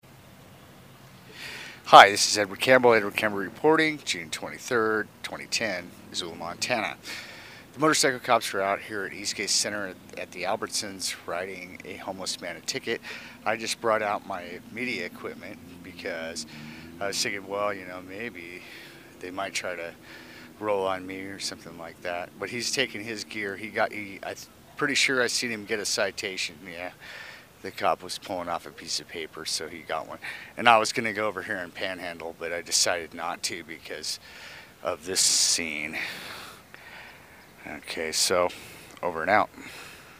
Live audio: Missoula cops cite beggar
The two officers involved in the sting were riding motorcycles.